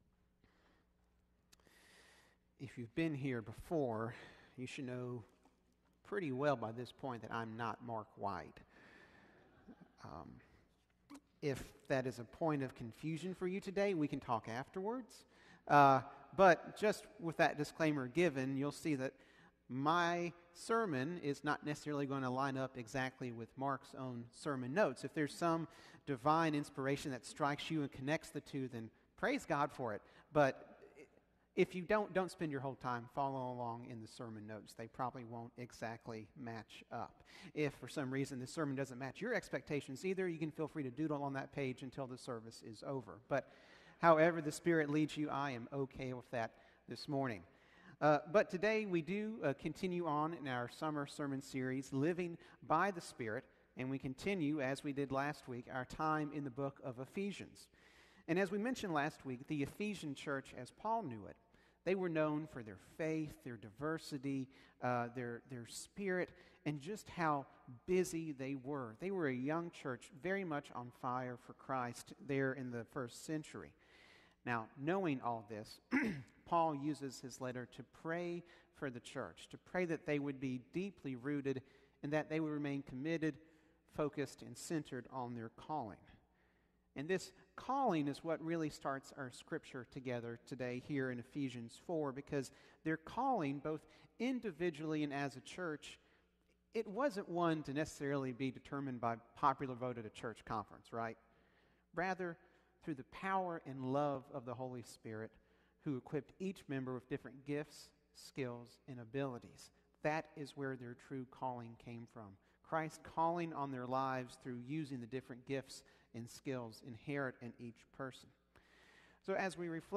Sermons | Forest Hills Baptist Church